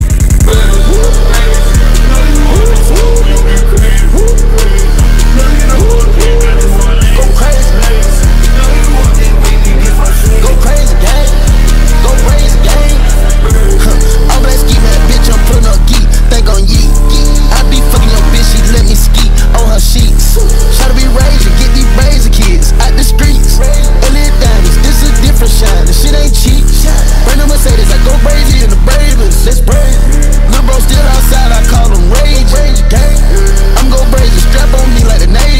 Kategori Marimba Remix